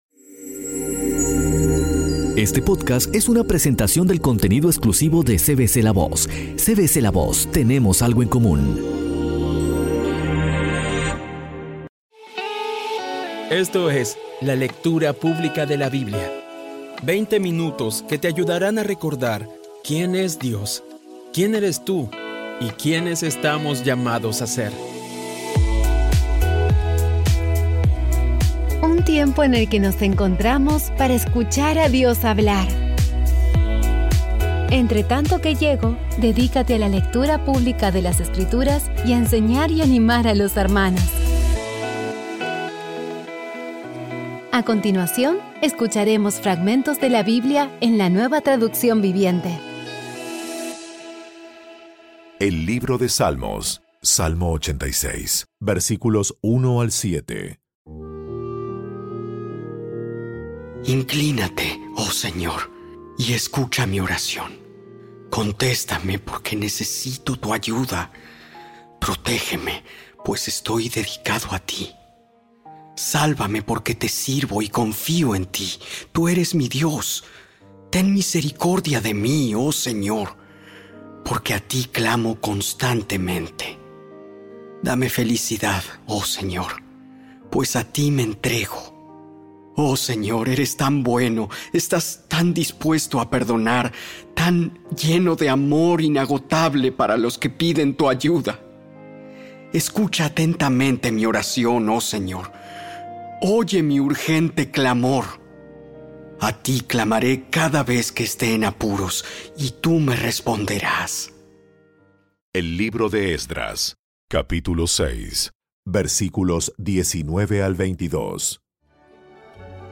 Audio Biblia Dramatizada Episodio 207
Poco a poco y con las maravillosas voces actuadas de los protagonistas vas degustando las palabras de esa guía que Dios nos dio.